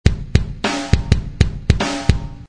ここではSampleTankでシンプルなリズムを打ち込んでみました。
SampleTankで打ち込んだ音をRenderしたフレーズ・サンプル（MP3）